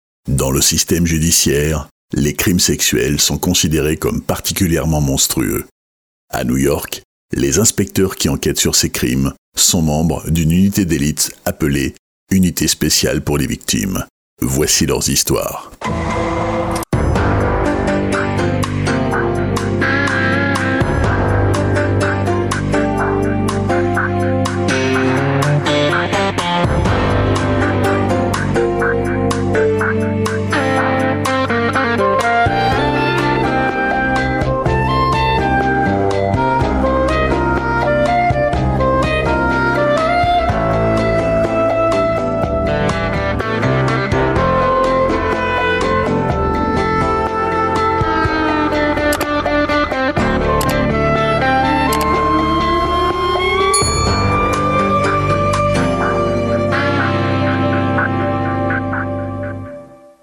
Narrations